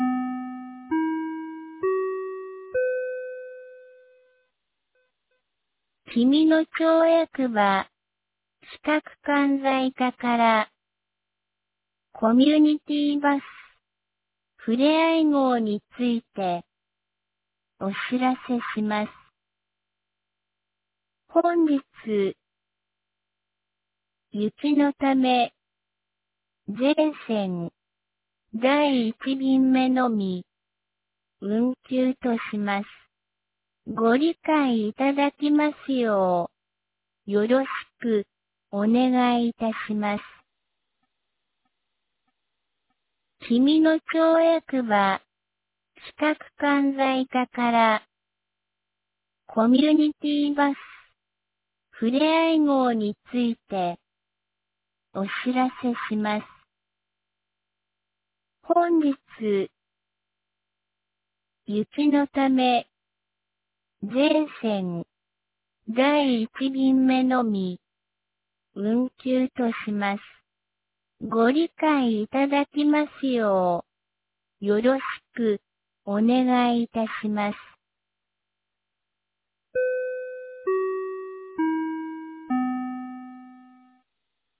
2026年02月09日 07時08分に、紀美野町より全地区へ放送がありました。